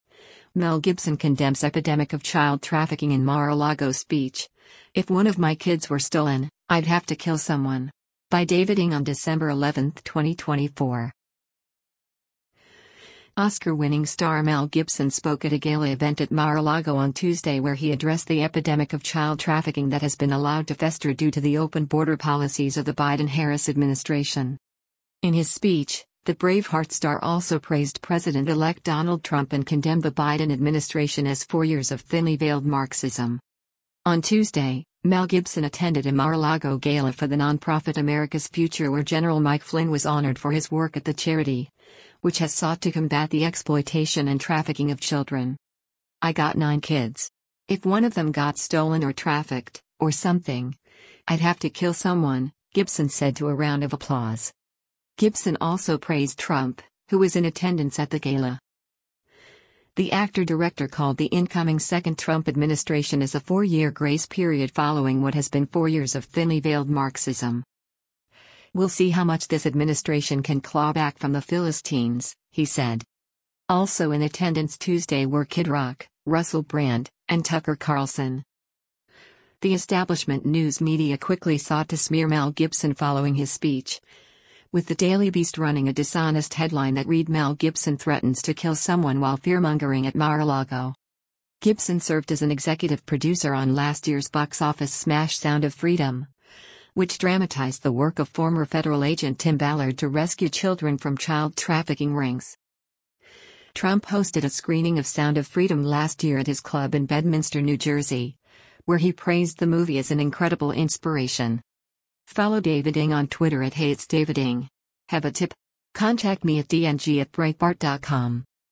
Oscar-winning star Mel Gibson spoke at a gala event at Mar-a-Lago on Tuesday where he addressed the epidemic of child trafficking that has been allowed to fester due to the open border policies of the Biden-Harris administration.
“I got nine kids. If one of them got stolen or trafficked, or something, I’d have to kill someone,” Gibson said to a round of applause.